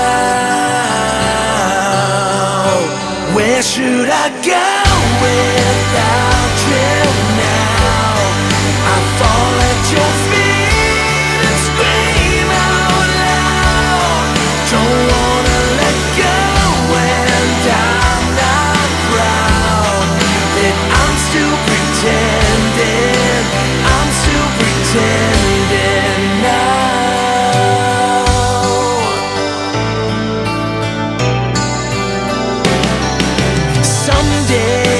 Жанр: Рок
Hard Rock